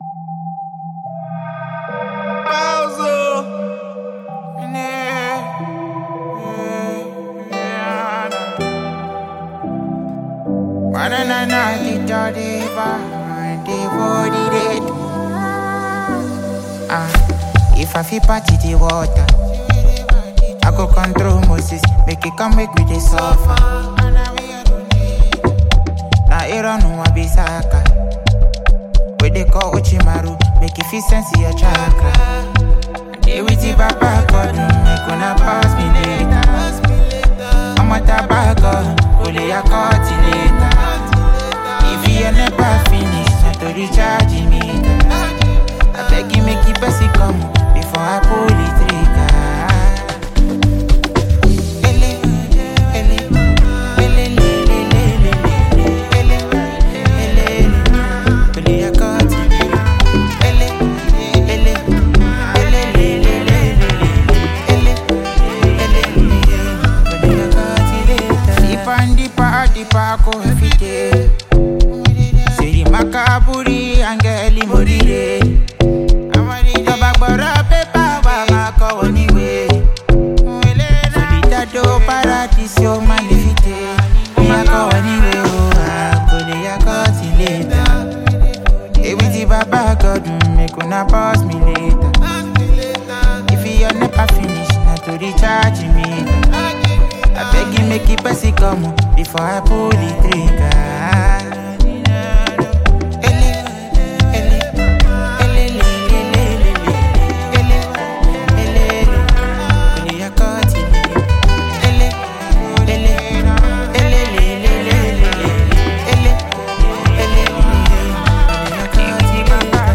Afrobeats
With nice vocals and high instrumental equipments